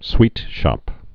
(swētshŏp)